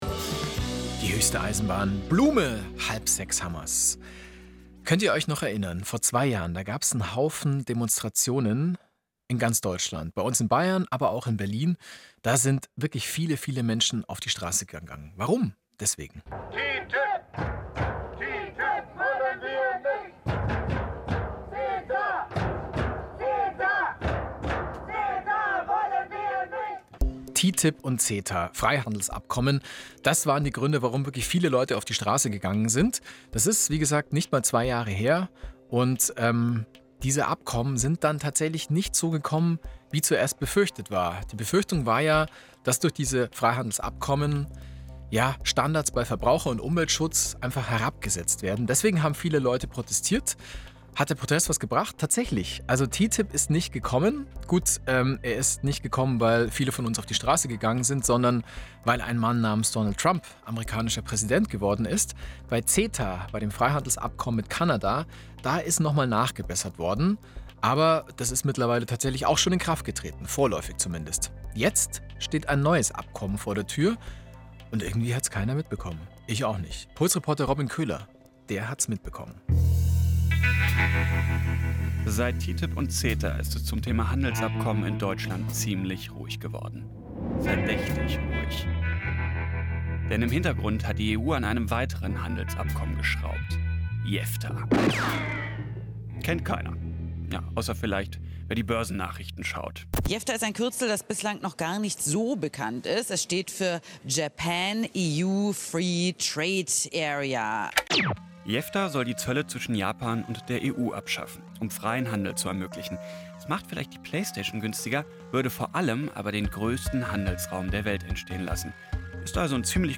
in an interview with Bayerischer Rundfunk Puls